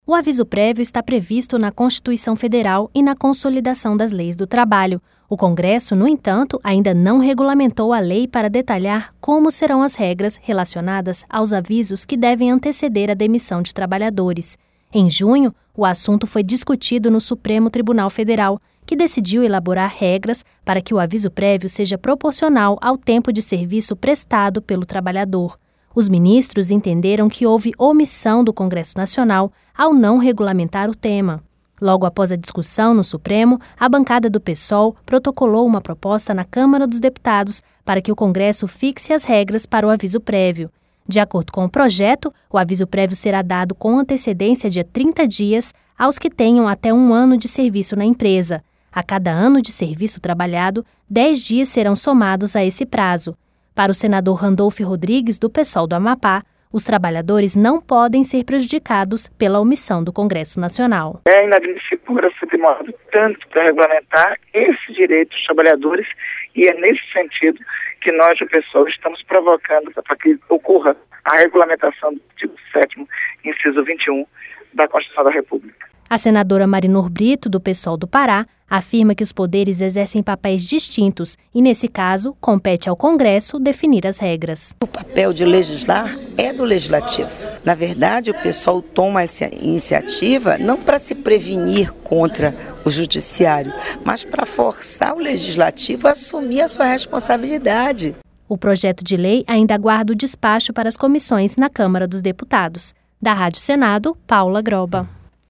A senadora Marinor Brito, do PSOL do Pará, afirma que os Poderes exercem papéis distintos e nesse caso compete ao Congresso definir as regras.